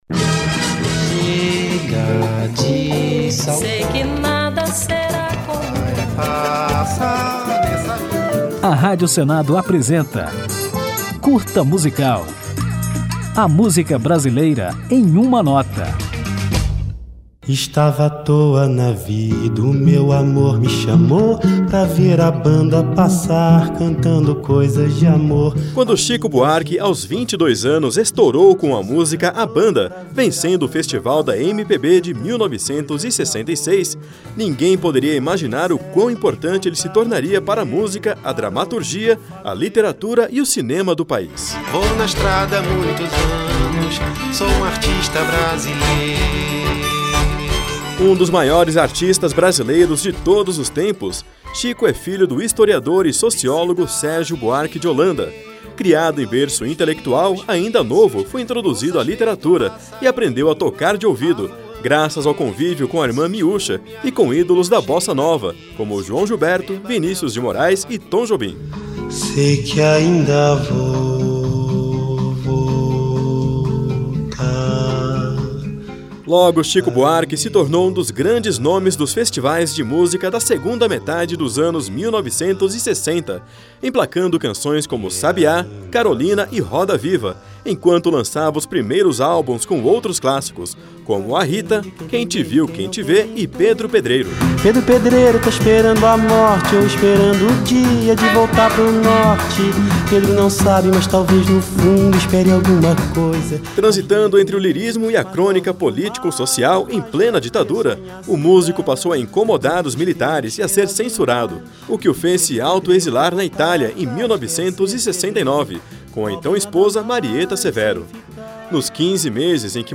Quando Chico Buarque, com apenas 22 anos, estourou com a música A Banda, vencendo o Festival da MPB de 1966, ninguém poderia imaginar o quão importante ele se tornaria para a música, a dramaturgia, a literatura, o cinema , a cultura e a política do Brasil. É o que você confere neste Curta Musical, que ainda toca a música Apesar de Você, censurada nos anos 70 e que virou símbolo de resistência política no país.